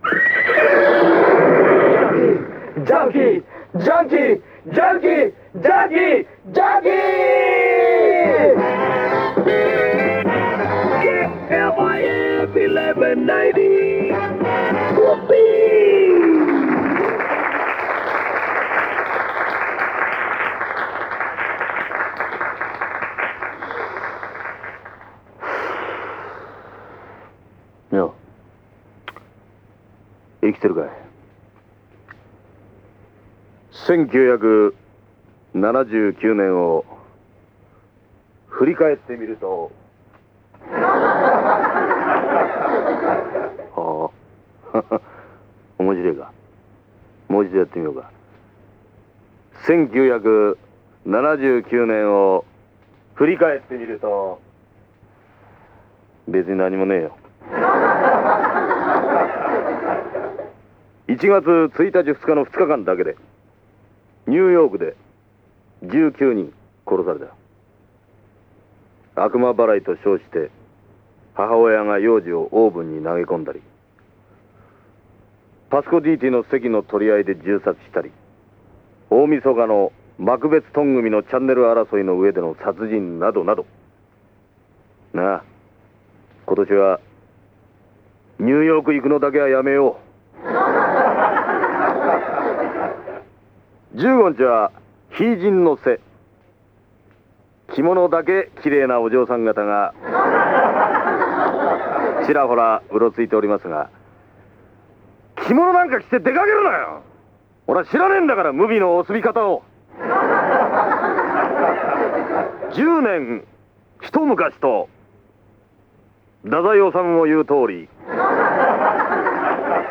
TBSラジオエアチェック